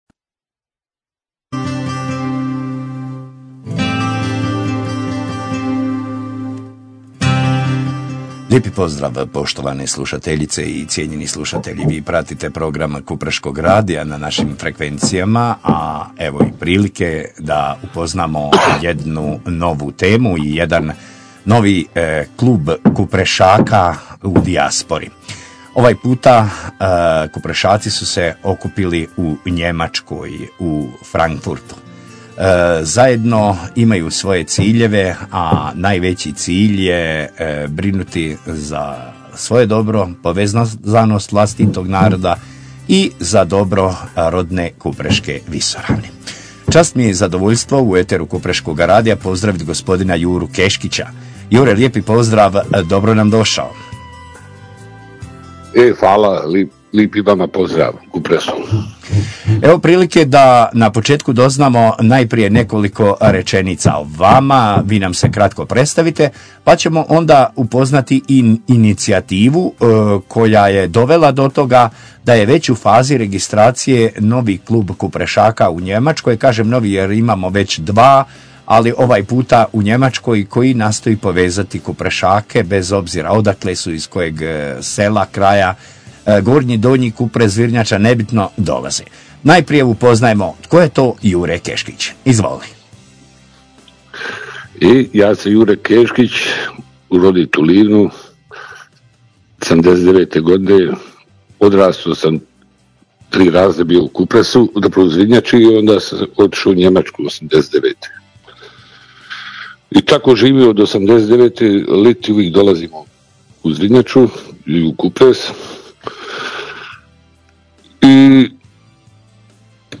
NAJAVA I RAZGOVOR: KUPREŠKA NOĆ U FRANKFURTU